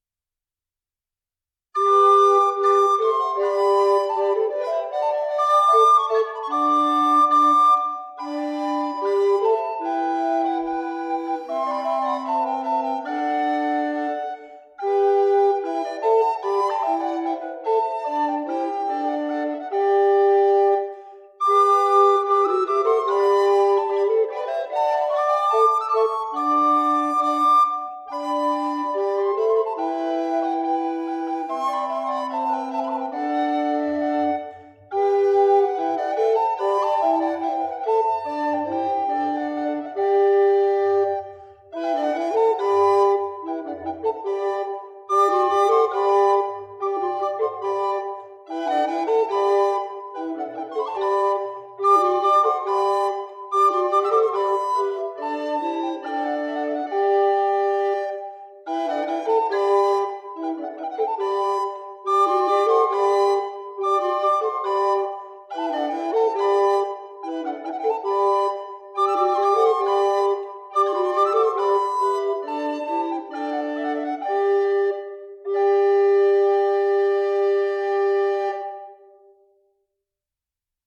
Ministriles coloniales de Guatemala
Música tradicional